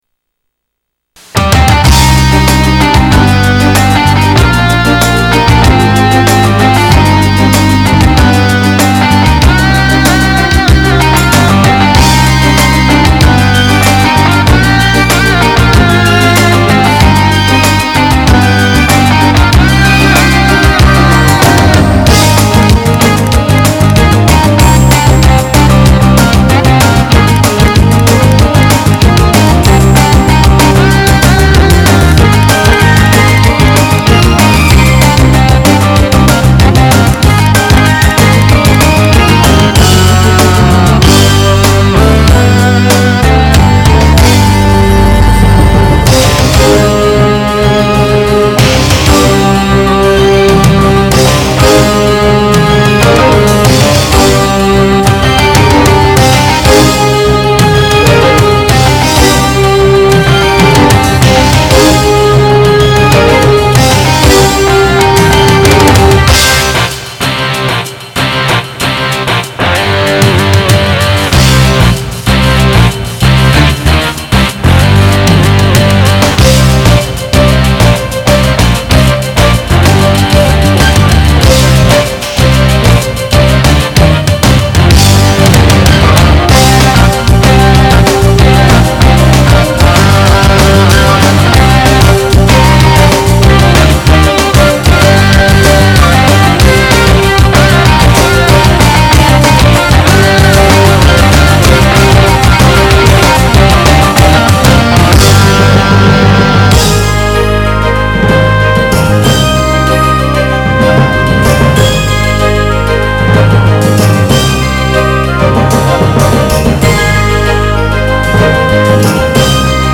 Power Metal